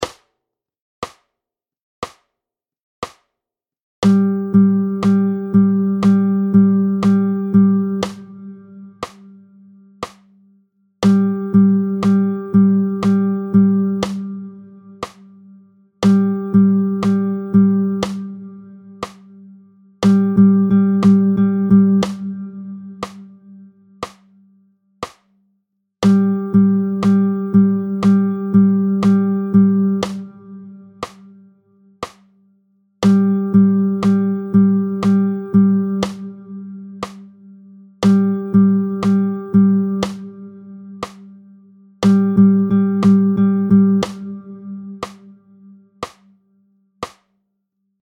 22-01 Les rythmes binaires, tempo 60